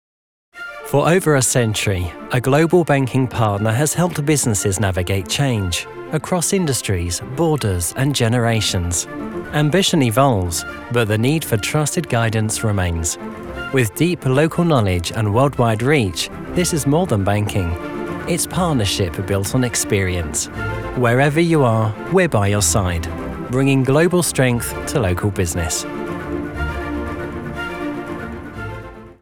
Corporate Banking - Inspiring
Gender: Male
Description of voice: I have a neutral British accent. My voice has a fresh, clear, measured and self-assured tone. Friendly, but authoritative if needed!
Home Recording Studio
Microphones: Neumann TLM 102